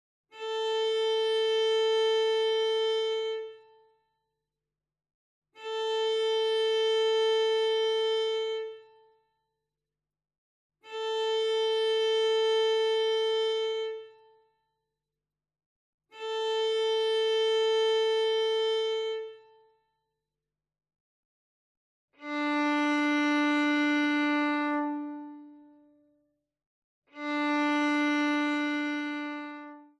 Besetzung: Violine
01 - Stimmtöne
VHR 3803_1 Stimmtöne.mp3